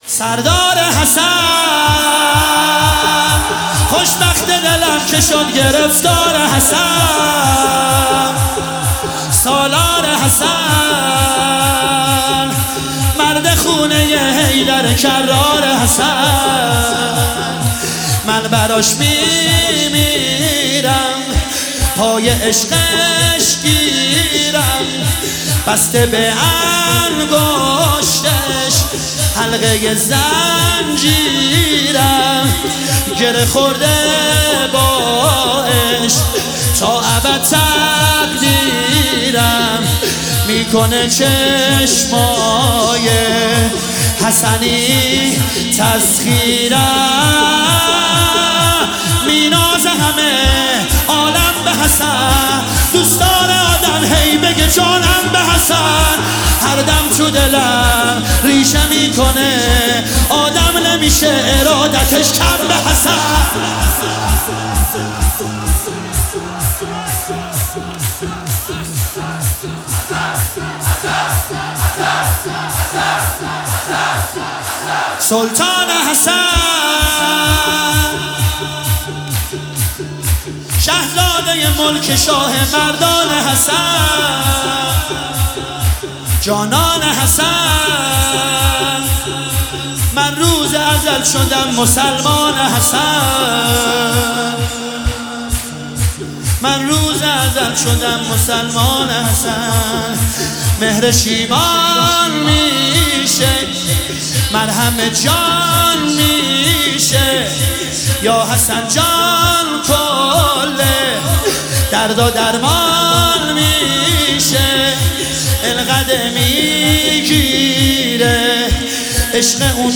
محفل زوارالبقیع طهران
مداحی شور جدید